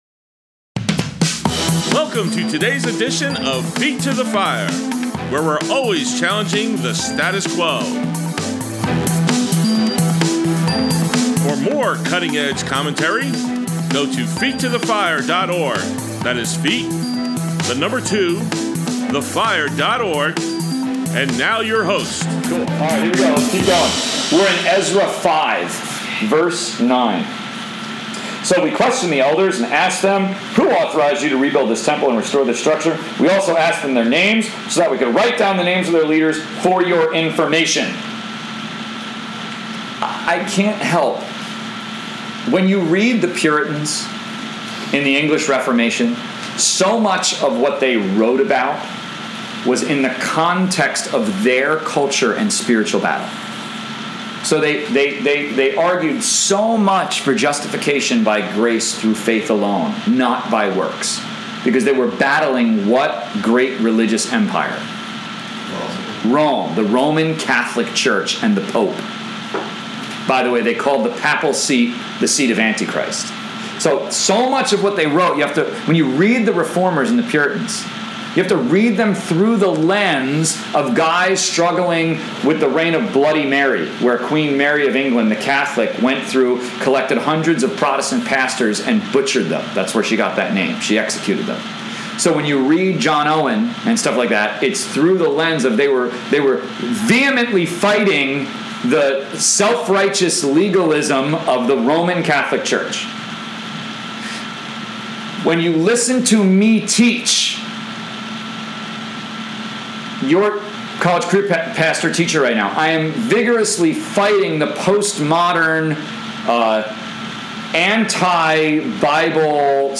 Saturday Sermons, 9/26/20